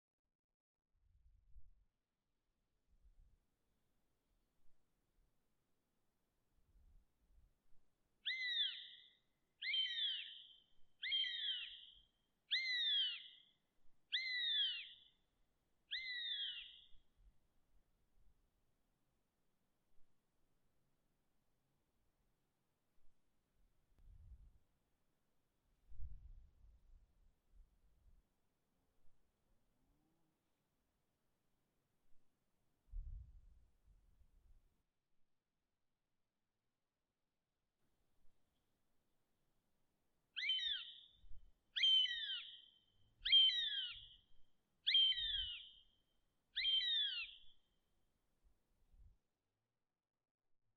Fågel- och däggdjursläten
Ibland har ljudinspelningen gjorts med kameran.
Duvhök   Krokagöl 25 juli 2024